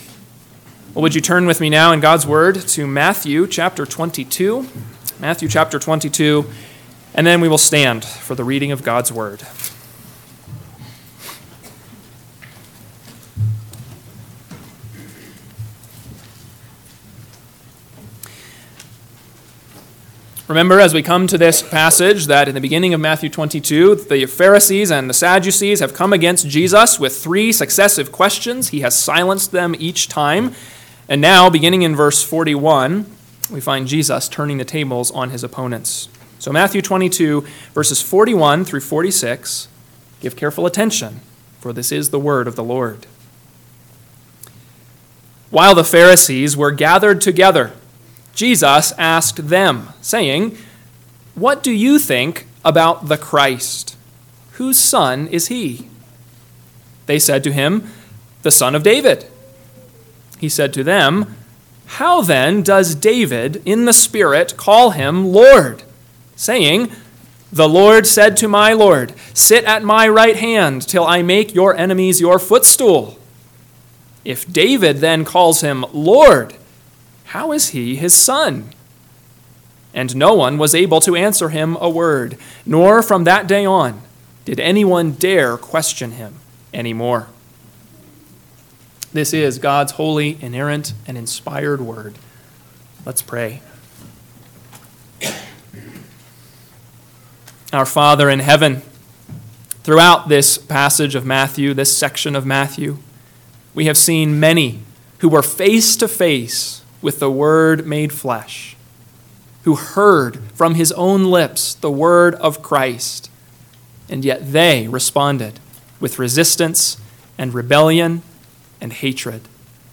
AM Sermon – 11/3/2024 – Matthew 22:41-46 – Northwoods Sermons